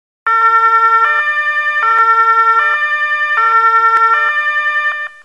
Sirenensignale/Martinshorn
Horn.mp3